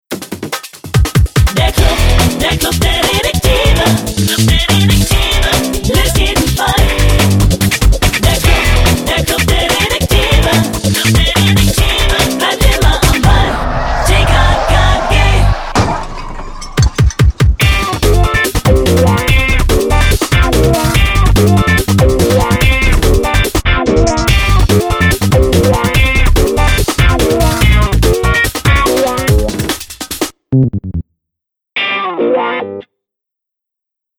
Titelsong der sechsten und siebten Staffel